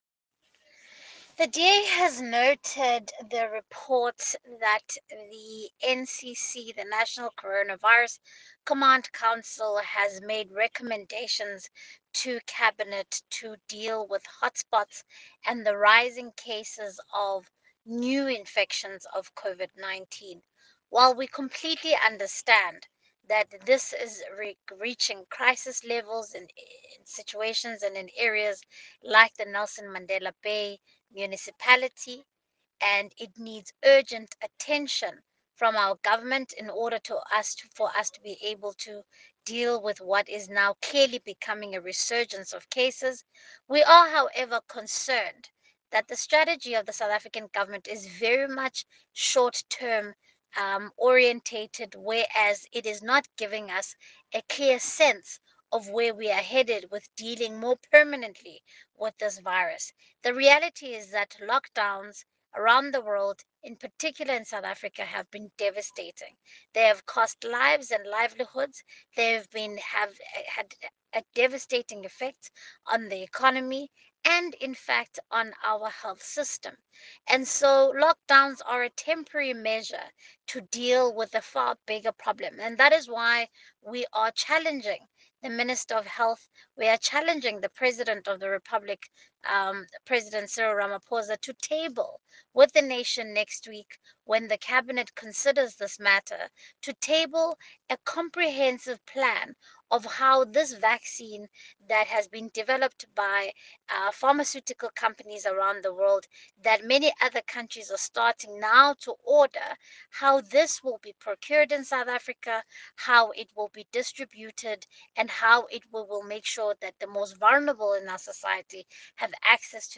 Issued by Siviwe Gwarube MP – DA Shadow Minister of Health
soundbite by Siviwe Gwarube MP.